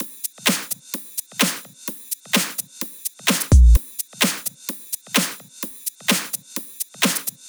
VDE 128BPM Close Drums 2.wav